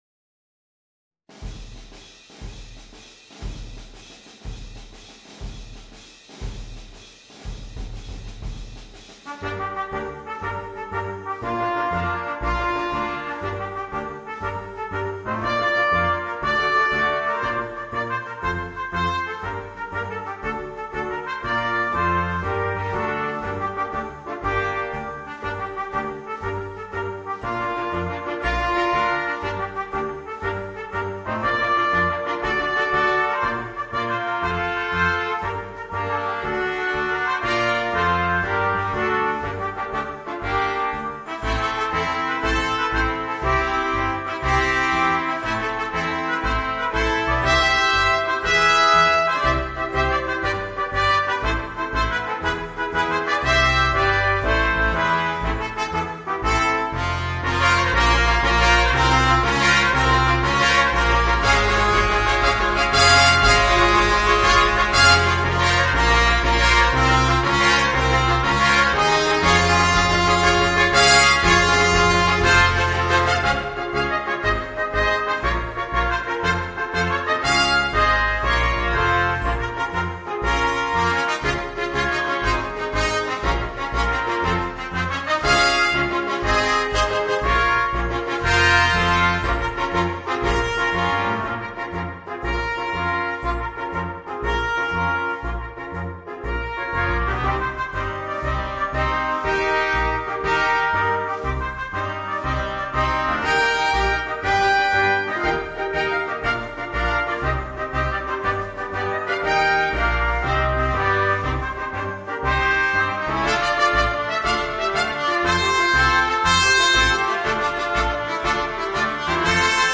Voicing: 4 Trumpets